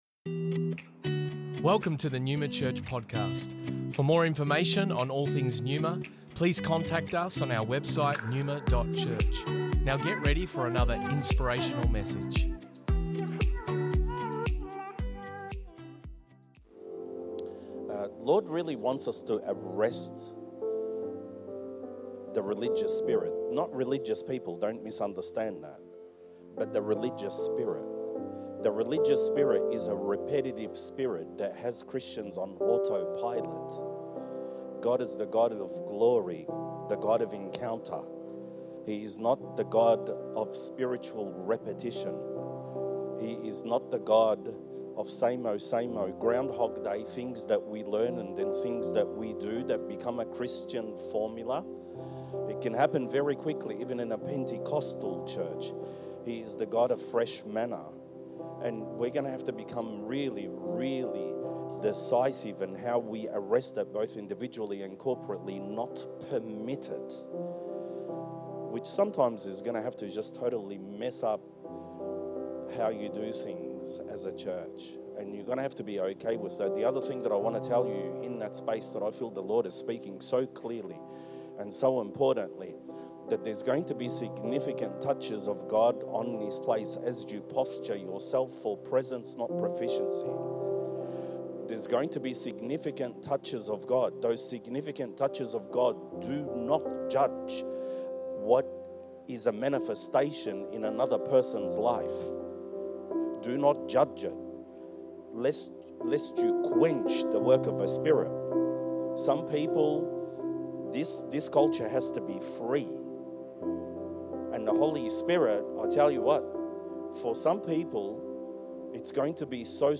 Originally recorded at Neuma Melbourne West 2023